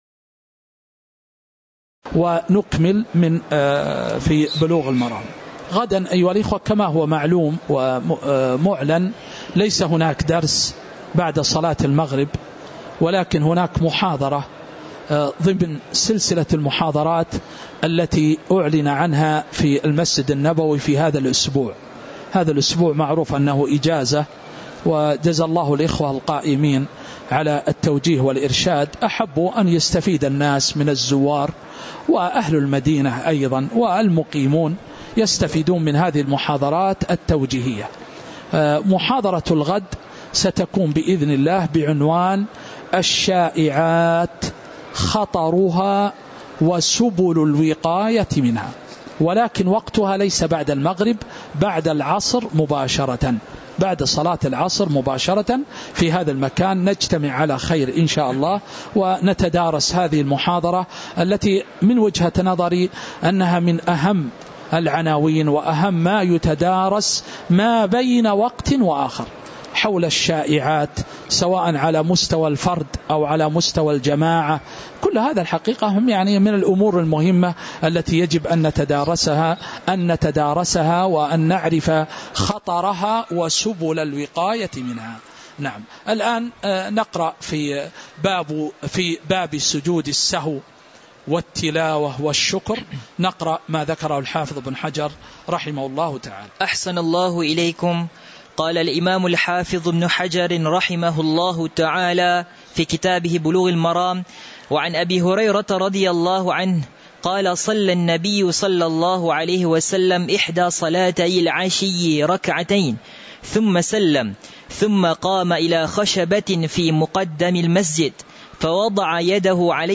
تاريخ النشر ٥ جمادى الأولى ١٤٤٥ هـ المكان: المسجد النبوي الشيخ